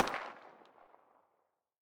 trachelium_veryfar.ogg